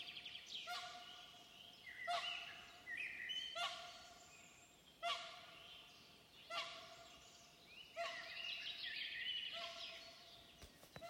Ziemeļu gulbis, Cygnus cygnus